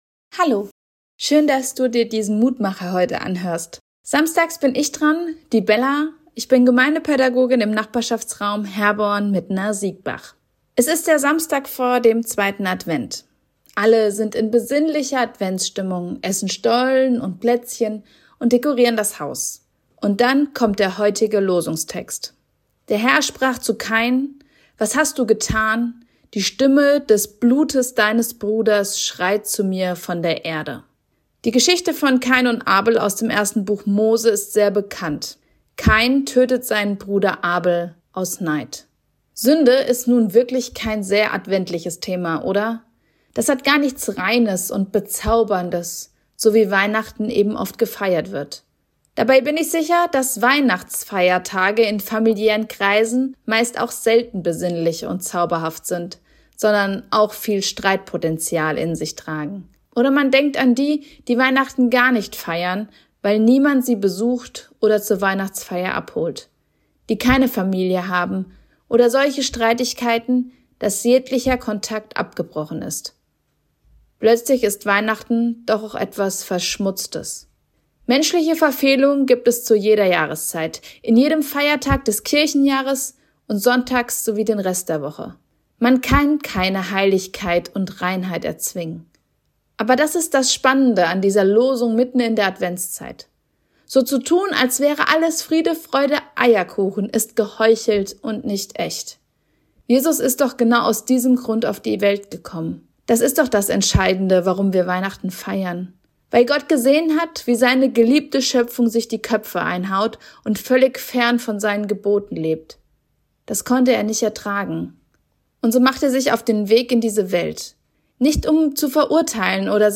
Mutmacher - Kleine Andacht zum Tag